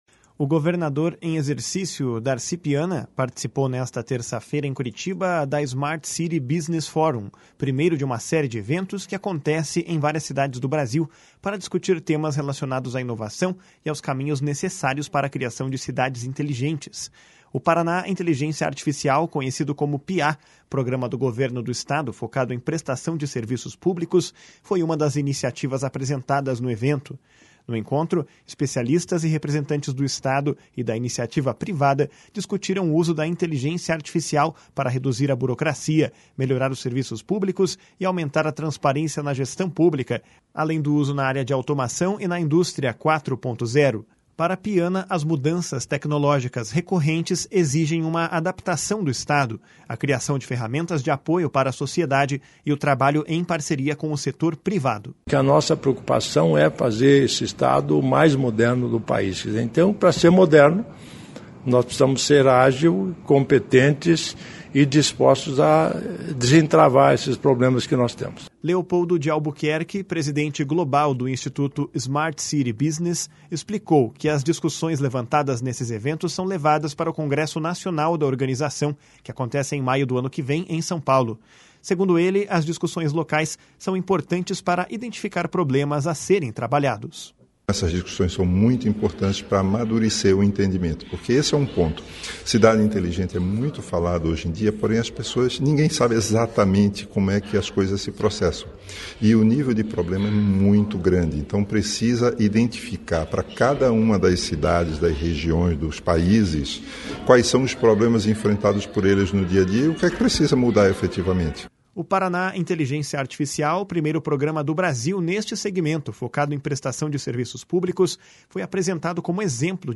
No encontro, especialistas e representantes do Estado e da iniciativa privada discutiram o uso da Inteligência Artificial para reduzir a burocracia, melhorar os serviços públicos e aumentar a transparência na gestão pública, além do uso na área de automação e na indústria 4.0. Para Piana, as mudanças tecnológicas recorrentes exigem uma adaptação do Estado, a criação ferramentas de apoio para a sociedade e o trabalho em parceria com o setor privado. // SONORA DARCI PIANA //